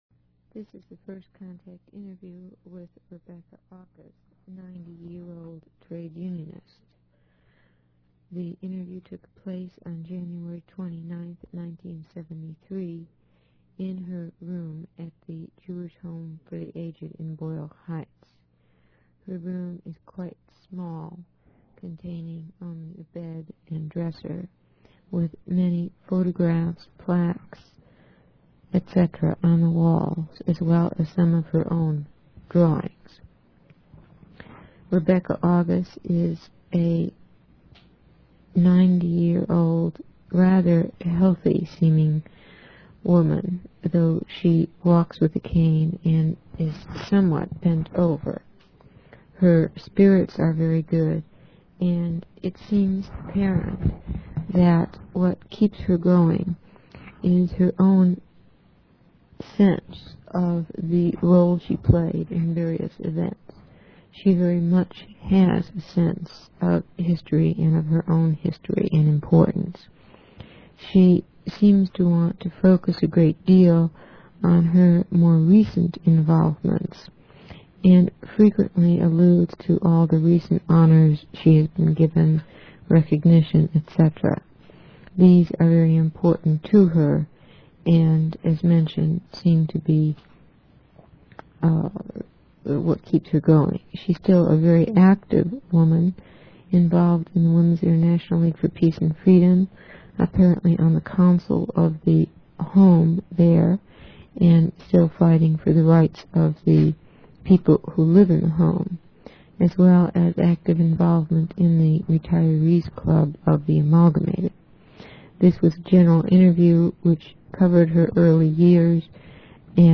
Because of the proximity to the freeway, the audio quality of the interview is only fair.
Tape introduction.